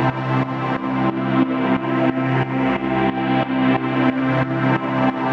GnS_Pad-dbx1:8_90-C.wav